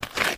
MISC Concrete, Foot Scrape 09.wav